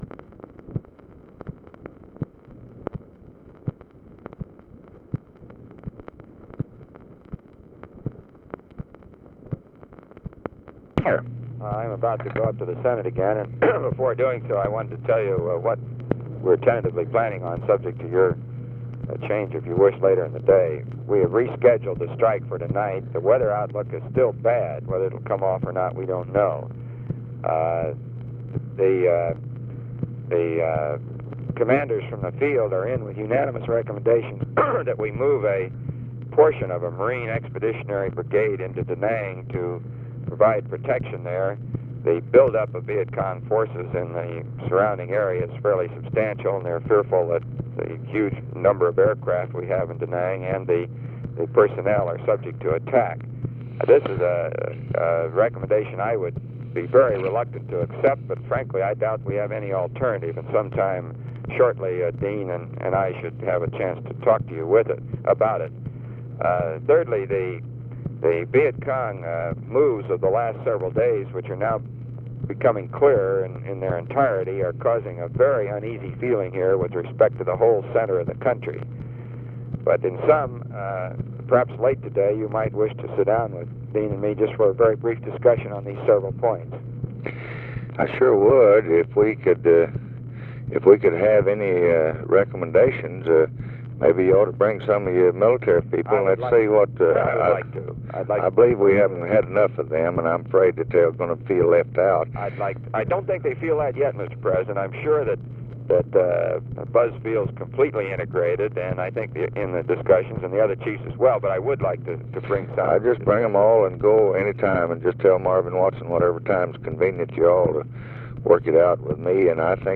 Conversation with ROBERT MCNAMARA, February 26, 1965
Secret White House Tapes